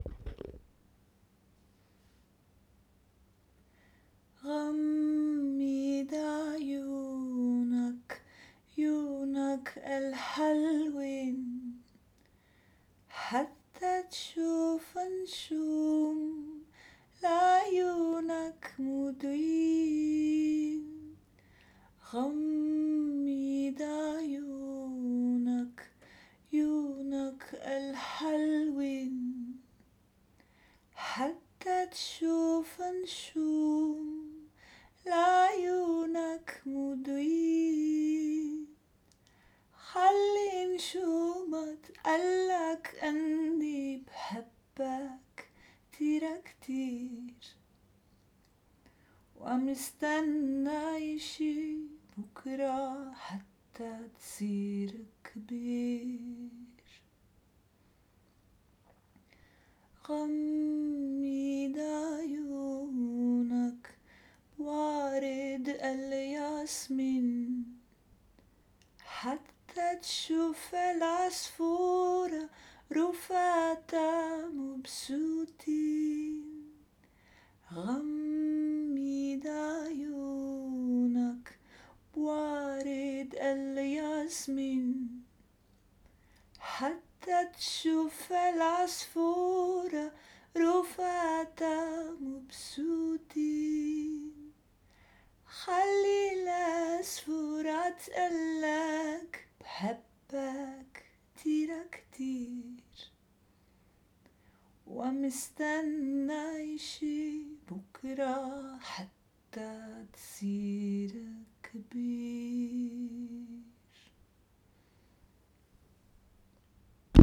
Lullaby